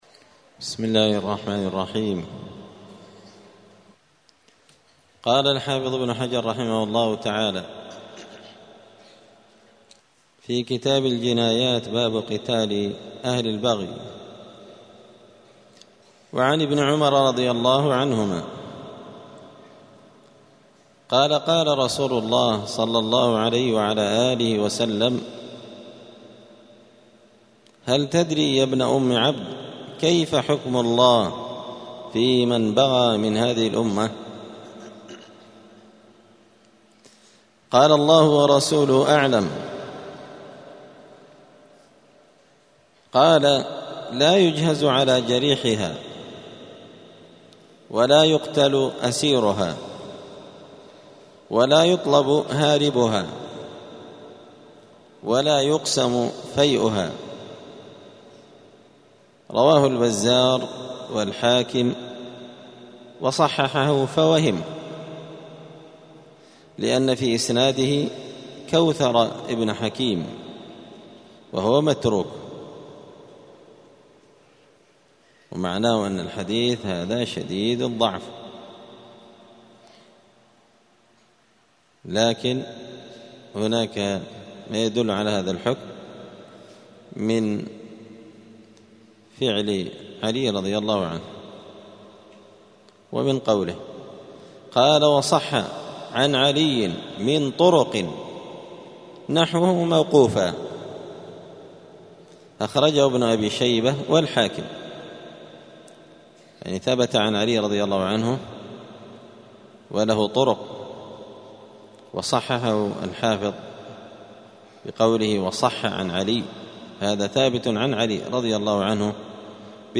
*الدرس الثالث والثلاثون (33) {باب قتال أهل البغي}*